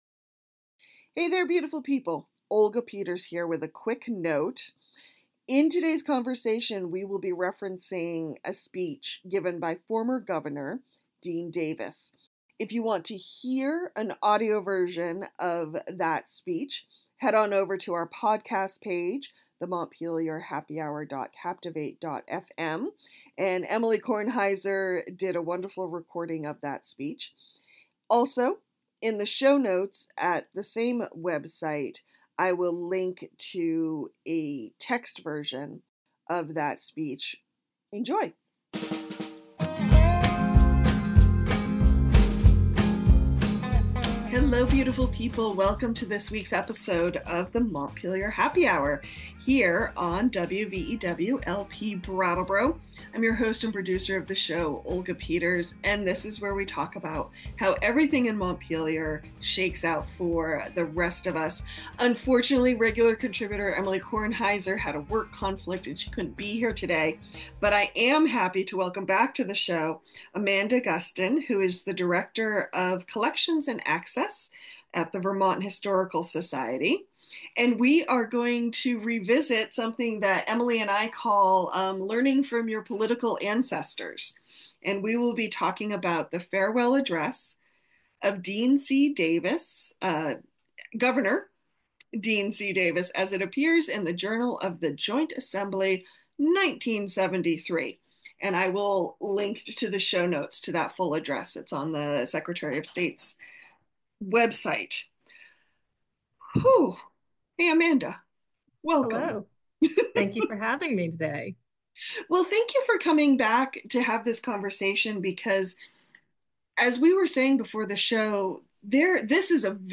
Davis is known for enacting the state's sales tax and land use law, Act 250. In this conversation, we use Davis' farewell address as a touchstone.